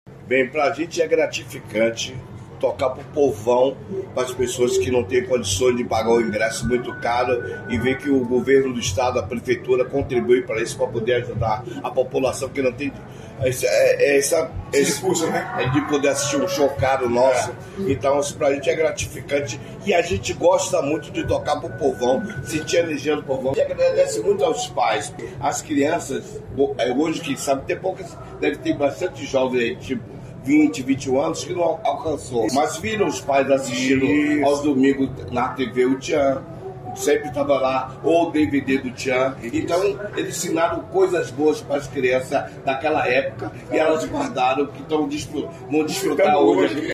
Sonora do cantor Compadre Washington sobre o show no litoral paranaense